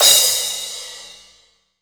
DJP_PERC_ (9).wav